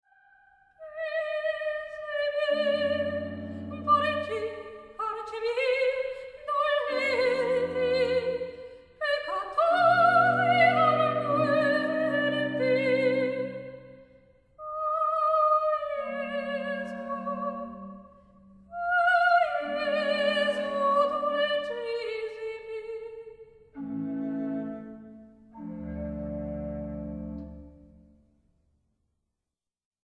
Motete para soprano, cuerdas y contínuo
11 Recitativo .36